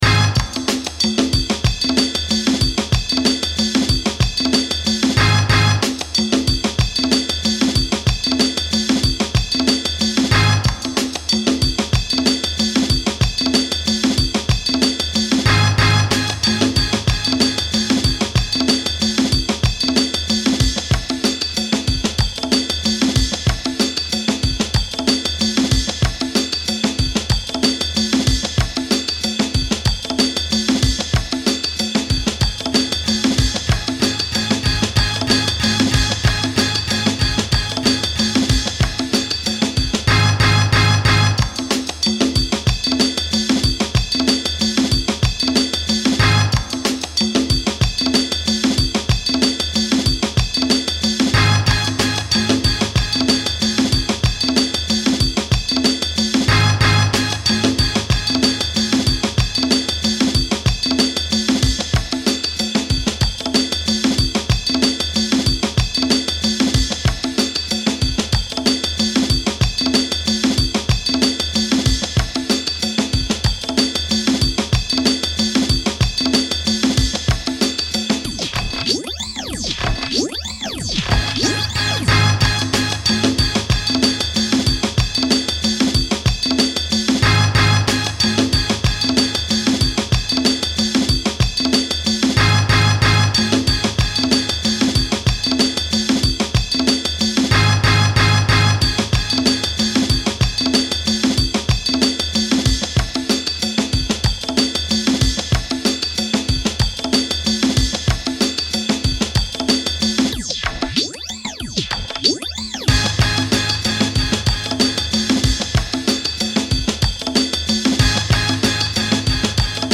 a remix solely constructed from a tiny breakbeat